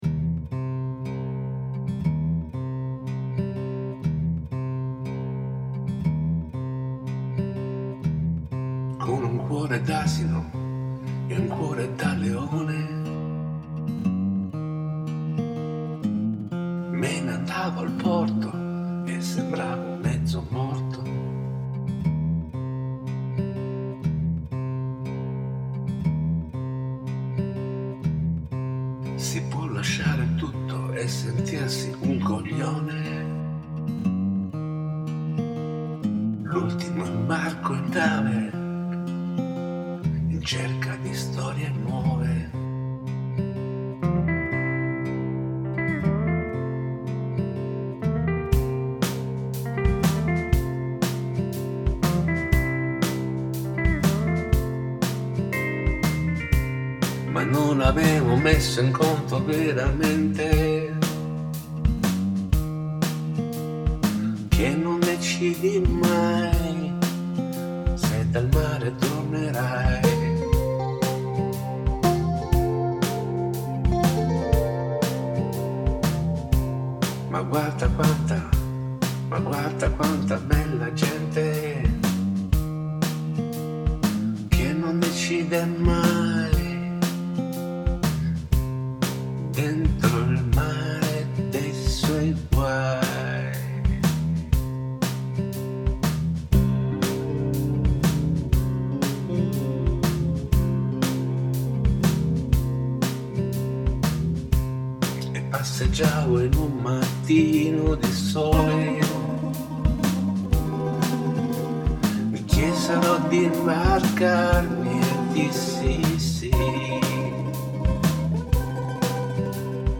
Buon ascolto - se volete ascoltarli così, grezzi e sporchi.